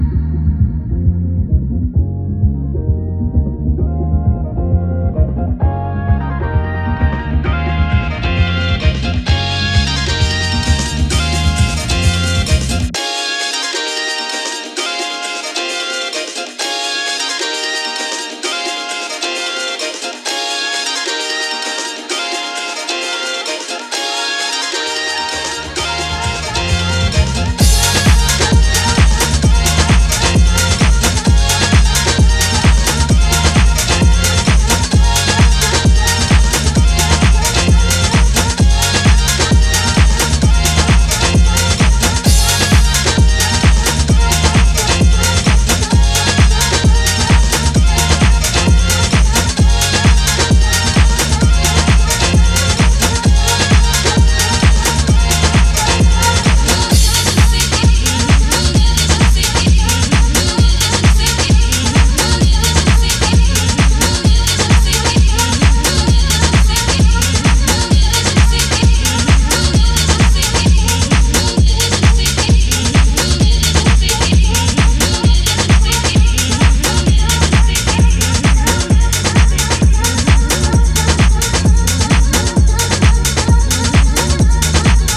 house